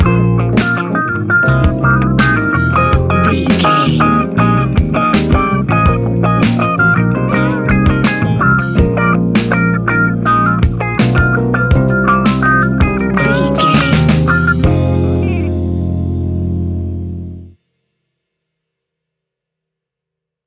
Epic / Action
Fast paced
In-crescendo
Uplifting
Ionian/Major
F♯
hip hop